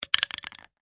roulette_ballstop.mp3